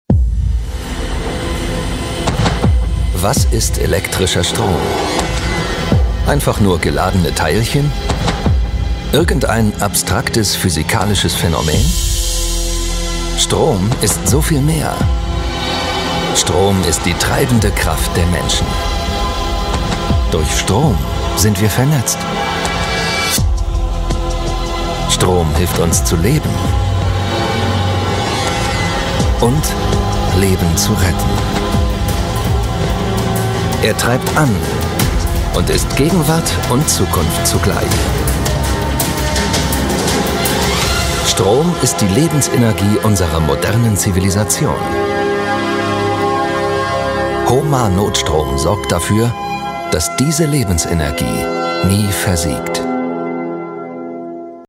•       IMAGEFILM